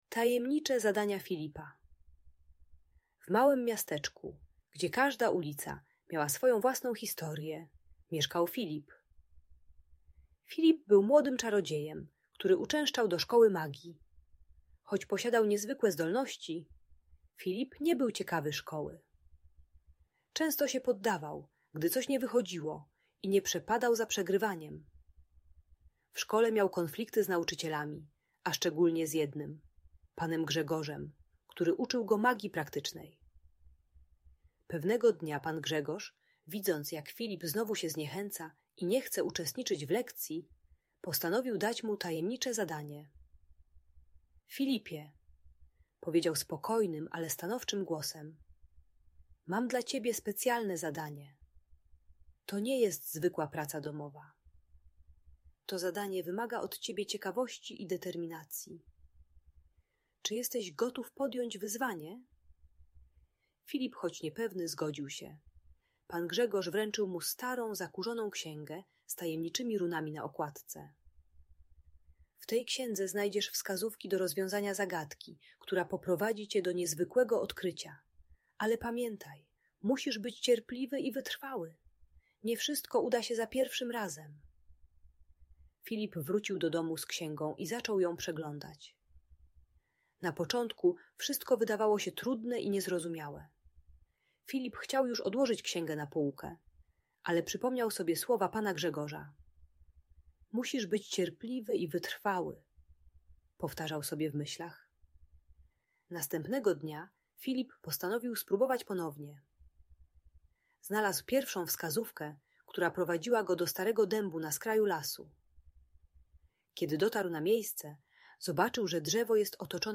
Historia Tajemniczych Zadań Filipa - Audiobajka